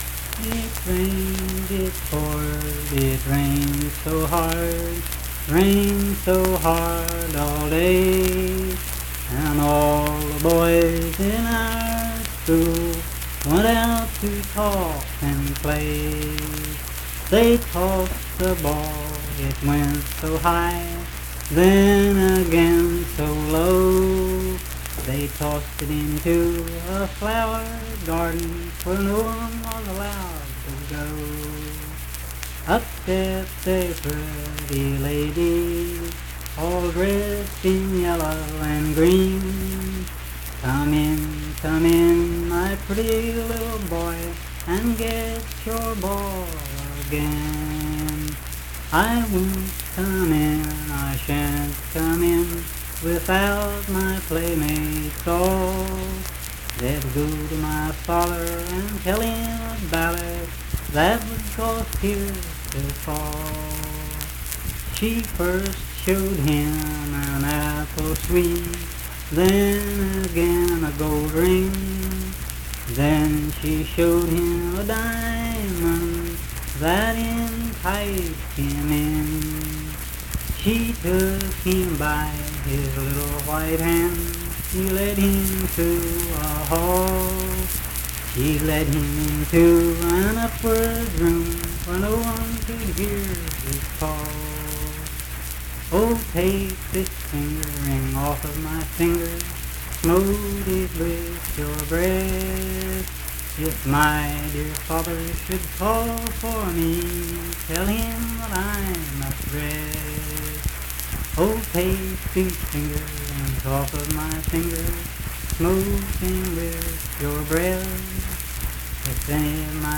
Unaccompanied vocal music
Verse-refrain 8(4).
Performed in Frametown, Braxton County, WV.
Voice (sung)